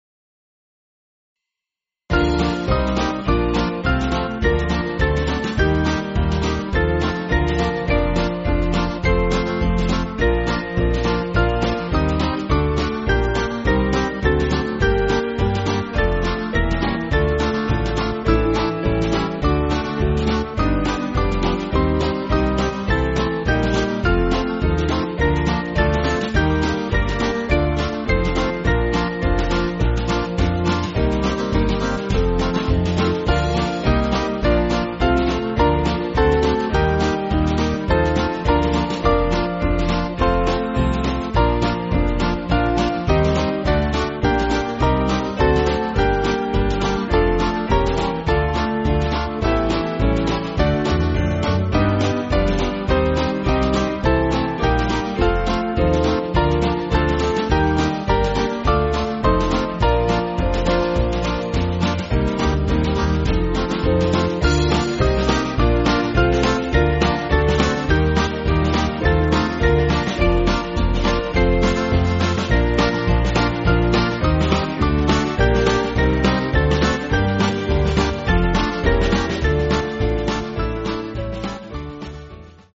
Small Band
(CM)   5/Bb